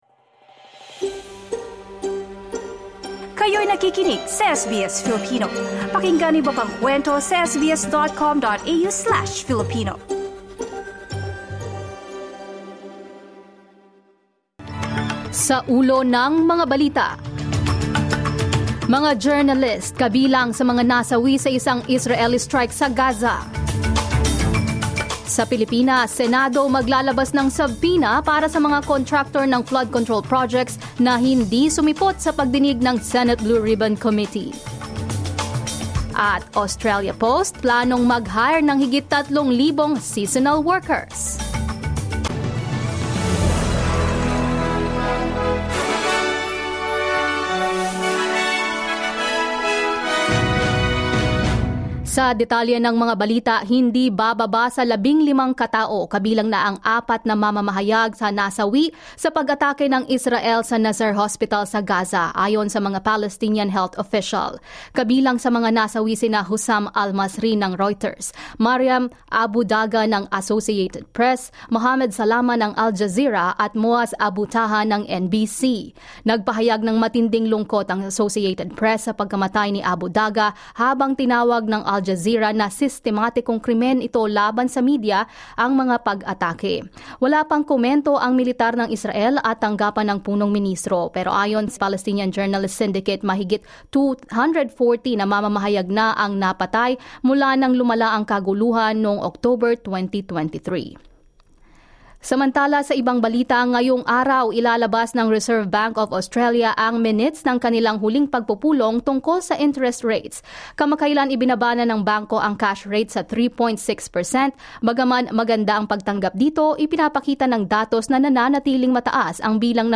SBS News in Filipino, Tuesday 26 August 2025